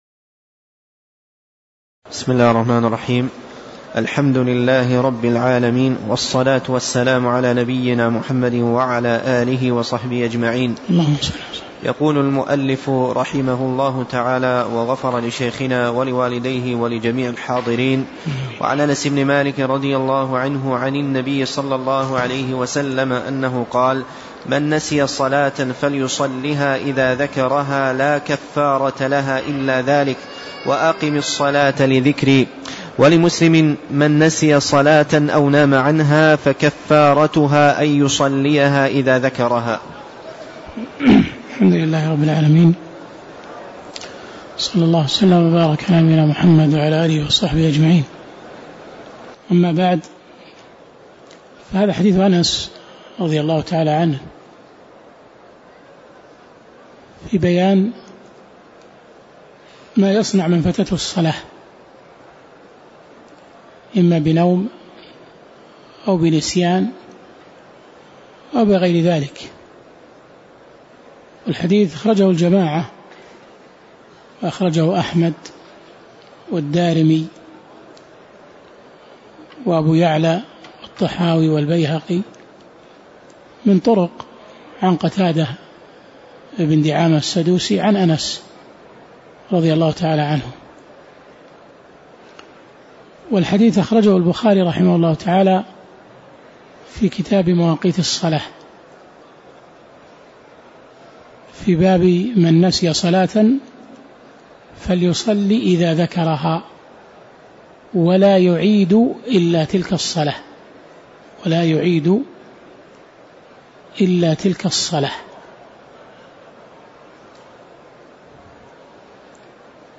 تاريخ النشر ١٥ ربيع الثاني ١٤٣٧ هـ المكان: المسجد النبوي الشيخ